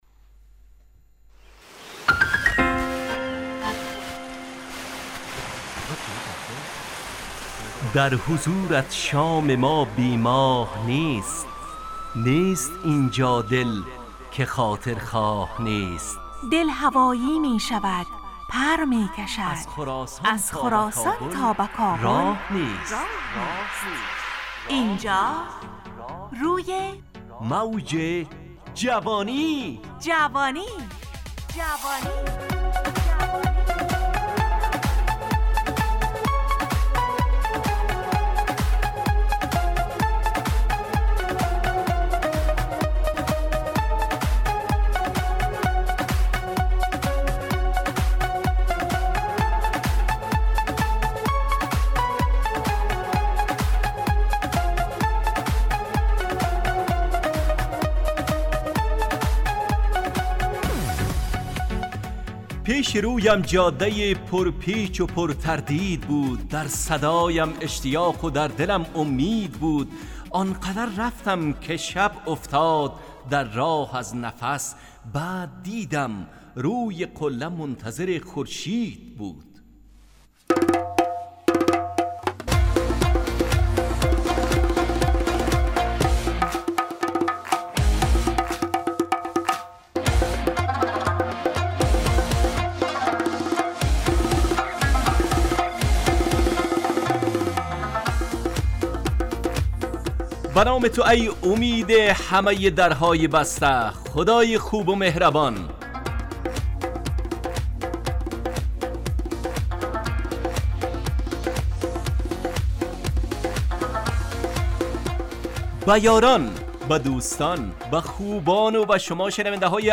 روی موج جوانی، برنامه شادو عصرانه رادیودری.
همراه با ترانه و موسیقی مدت برنامه 55 دقیقه . بحث محوری این هفته (امید) تهیه کننده